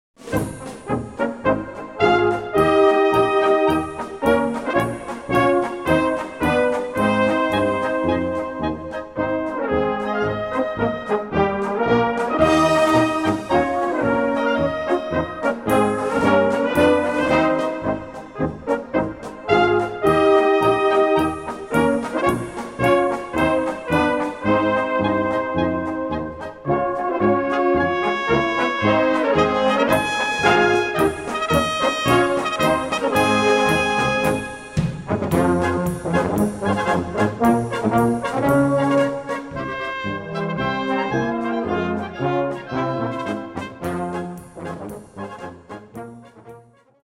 Gattung: Böhmische Polka
Besetzung: Blasorchester
Eine gemütliche, aber dennoch schwungvolle böhmische Polka